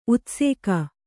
♪ utsēka